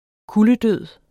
Udtale [ ˈkuləˌdøðˀ ]